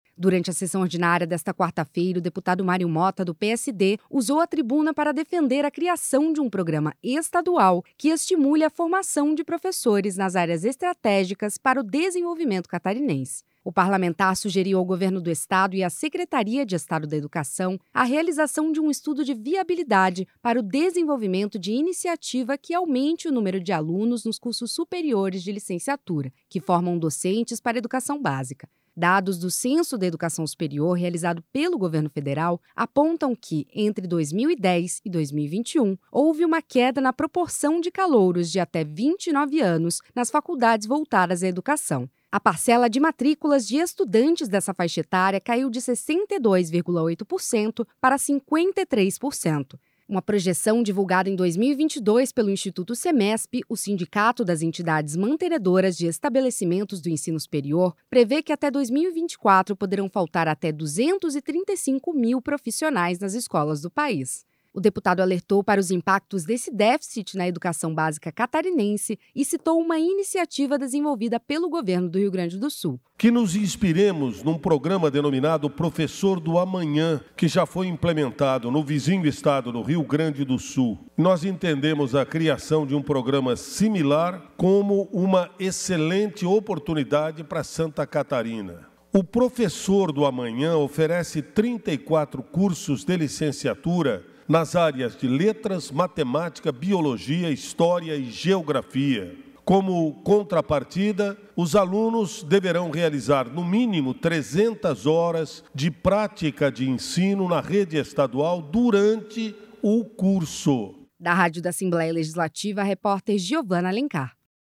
Durante a sessão ordinária desta quarta-feira (19), o deputado Mário Motta (PSD) usou a tribuna para defender a criação de um programa estadual que estimule a formação de professores nas áreas estratégicas para o desenvolvimento catarinense.
Entrevista com:
- deputado Mário Motta (PSD).
Repórter